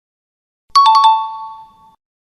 KEAoP3EF20z_Efecto-de-sonido-ACIERTO.mp3